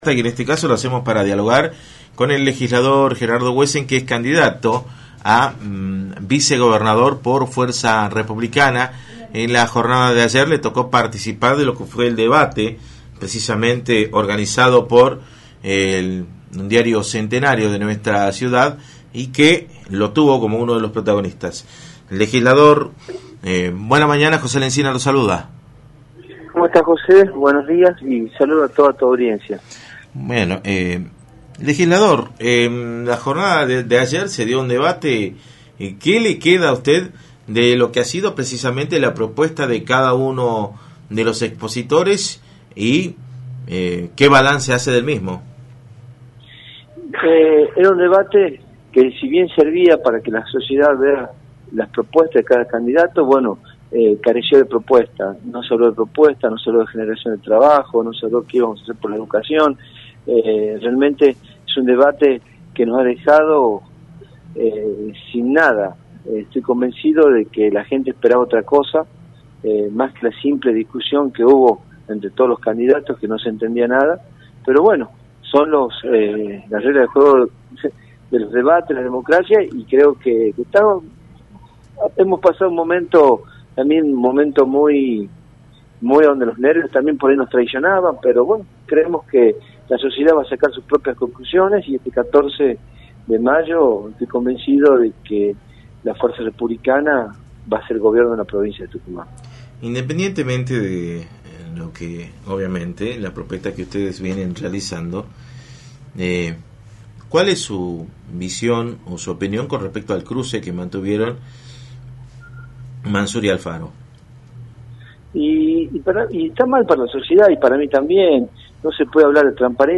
Gerardo Huesen, Legislador y candidato a Vicegobernador por Fuerza Republicana, analizó en Radio del Plata, por la 93.9, el debate de vicegobernador llevado a cabo ayer y aseguró que careció de propuestas.